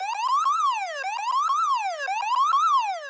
emergency.wav